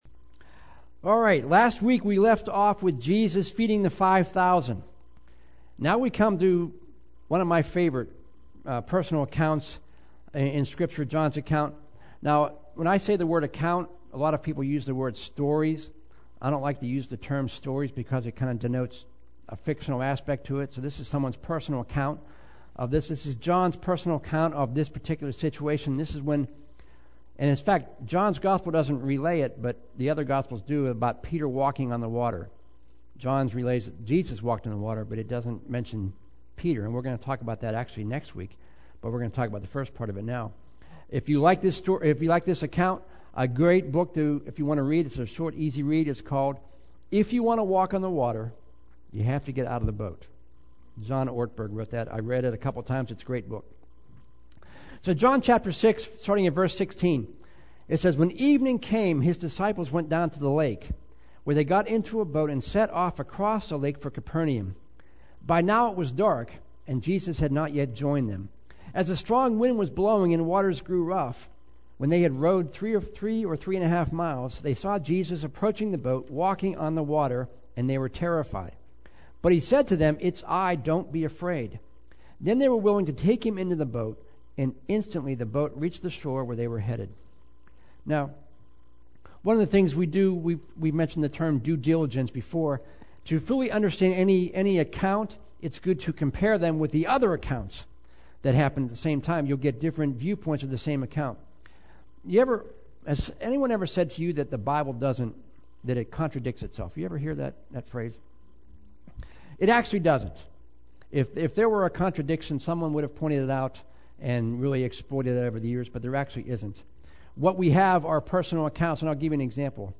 Today's sermon continued our look at Jesus' life as seen in the book of John.